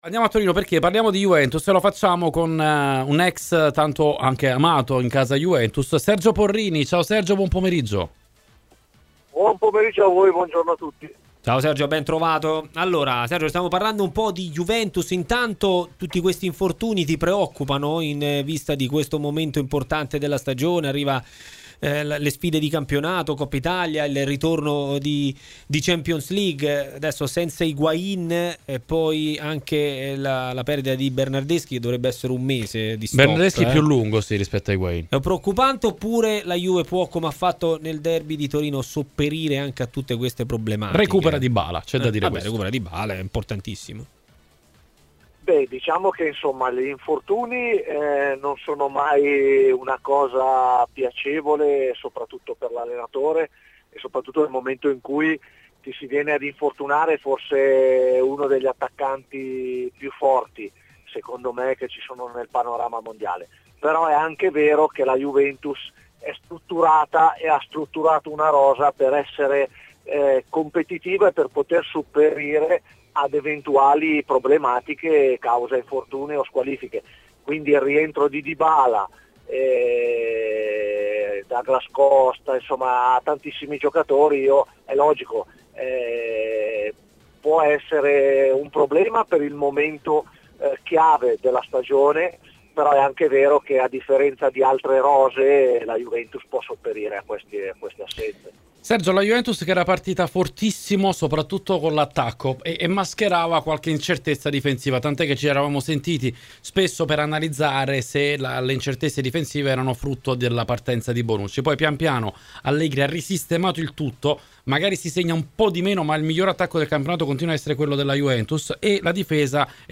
Per parlare di Juventus, su RMC Sport, nella trasmissione pomeridiana 'Maracanà', è stato contattato l'ex giocatore bianconero Sergio Porrini: